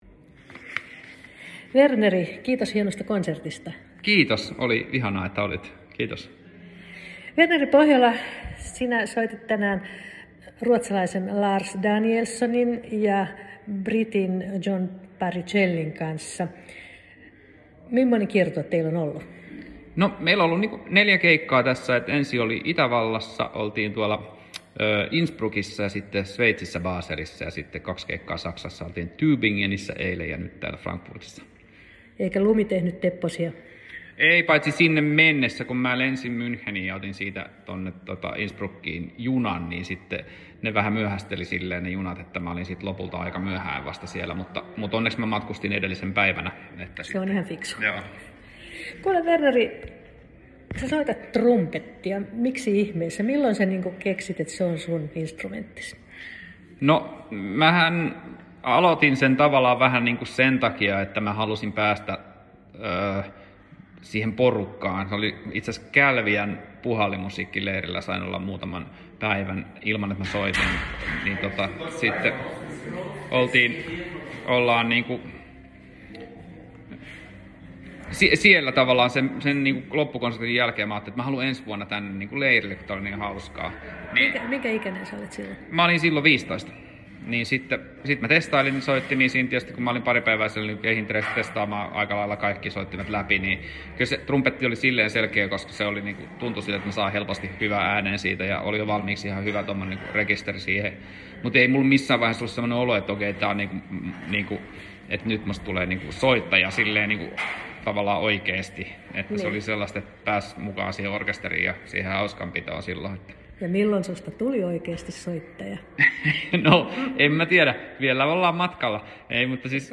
Verneri Pohjola esiintyi Frankfurtissa ja ehti antamaan haastattelun SISU-radiolle.
pahoittelemme äänenlaatua.